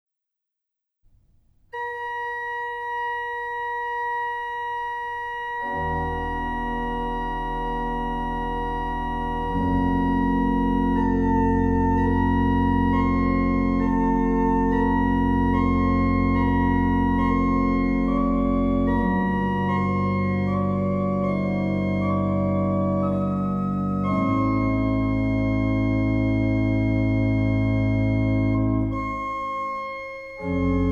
パイプオルガン
楽器：ピーター・バッカー・オルガン（1671年製作）
場所：聖ボニファシウス教会（オランダ・メデンブリック）